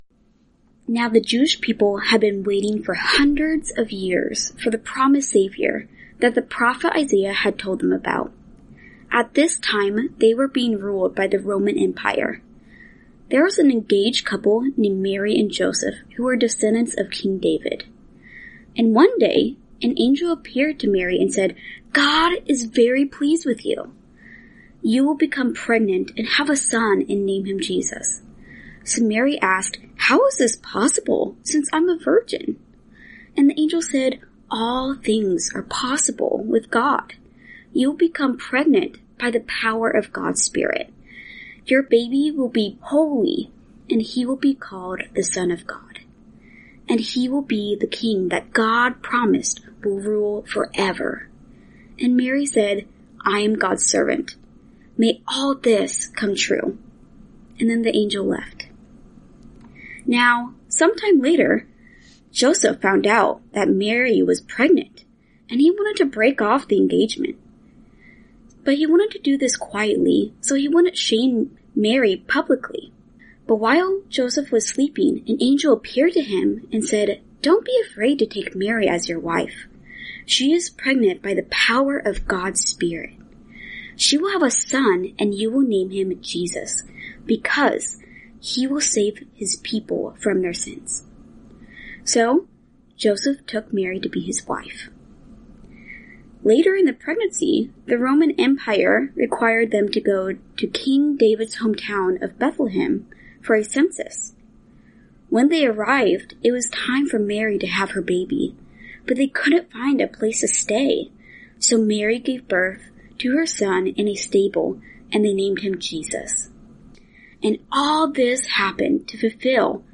This Advent season, anticipate the celebration of Christmas with oral Bible stories.